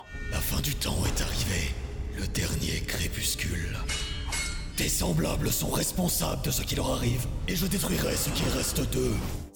中低音域の声で、暖かさと親しみやすさを兼ね備えています。
太い声(フランス語)